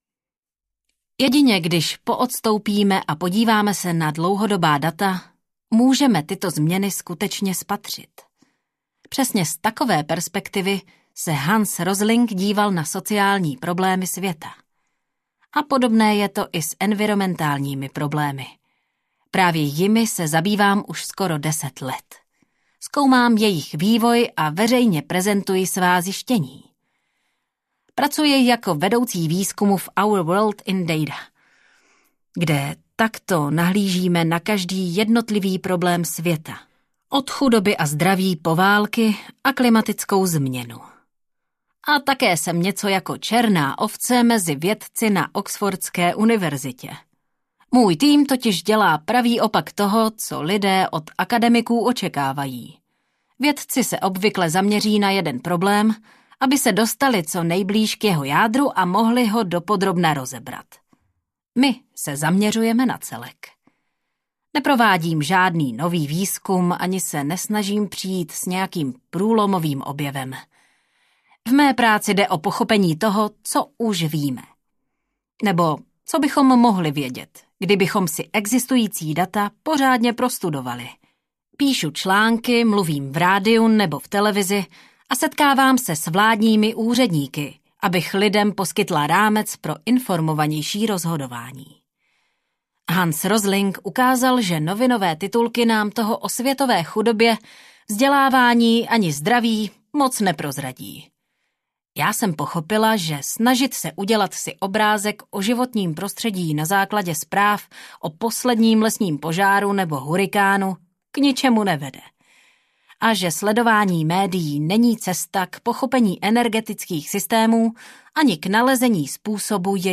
Audiokniha Není to konec světa - Hannah Ritchie | ProgresGuru